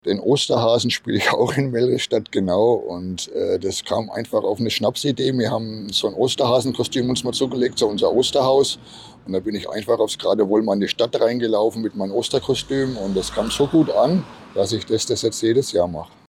Interview: Das Osterhaus in Mellrichstadt - PRIMATON